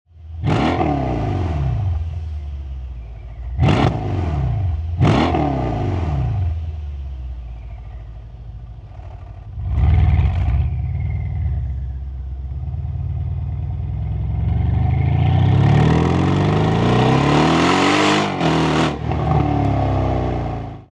Capristo bietet Ihnen eine exklusive Klappenabgasanlage für den Mercedes E63 Kombi 5.5L V8 Biturbo AMG 4matic S und 2WD (S 212 ab 2013 Facelift Model).
Mit dieser Klappenabgasanlage bringen Sie Ihren Mercedes neben dem optischen und technischen Anspruch nun zu dem passenden akustischen Sound.
Gasstoesse_anfahrt.mp3